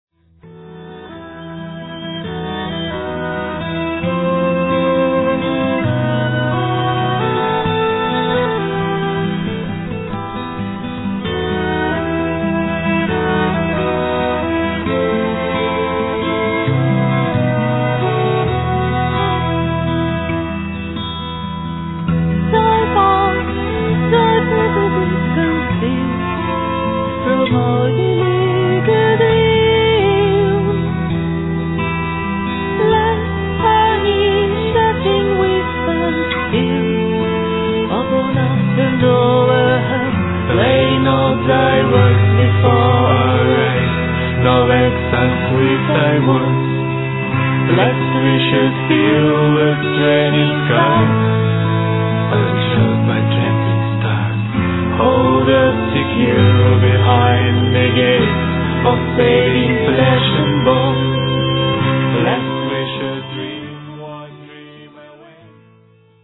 voice, acoustic guitar
voice, percussion, harmonica
flute, blockflute, tin-whistles
cello
violin
ガラス細工のように儚いアコースティックな音が幻想的。男女ヴォーカルの掛け合いも見事なまでにフィット。